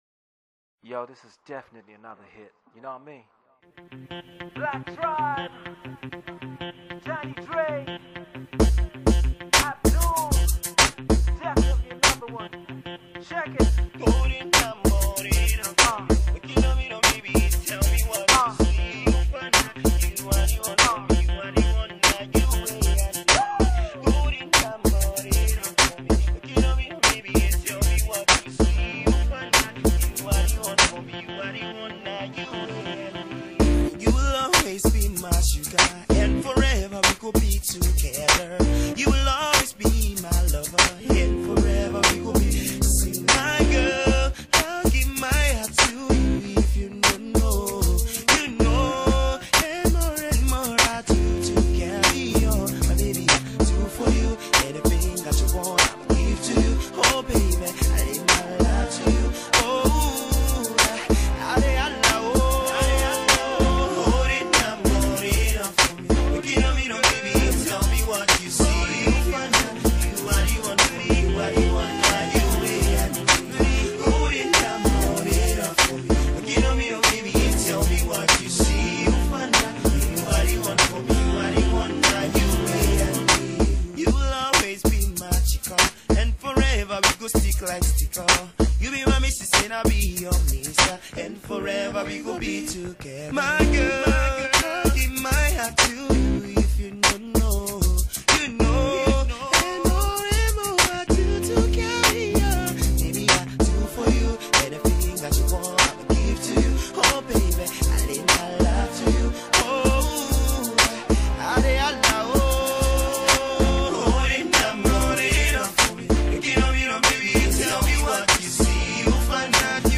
love, dance classic
Afro-pop/ Afro-Dancehall duo
party jam